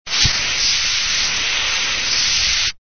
Descarga de Sonidos mp3 Gratis: aerosol.
aerosol.mp3